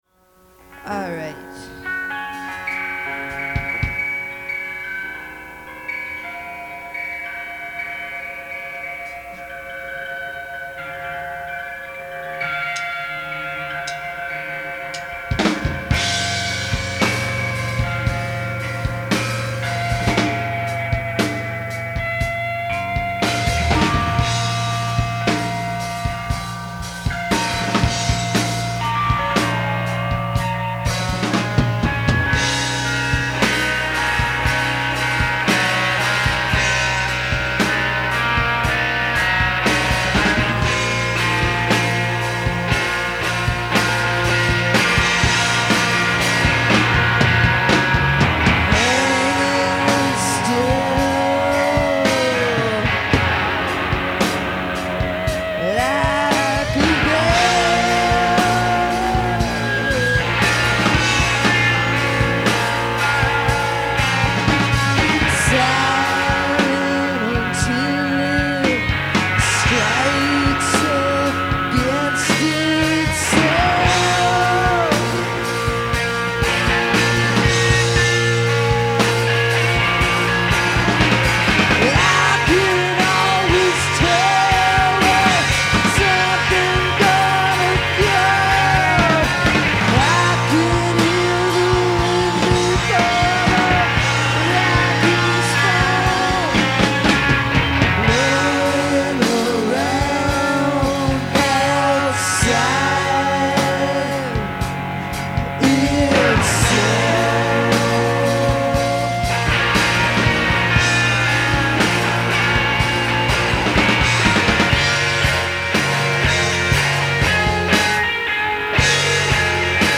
Vermonstress - Day 1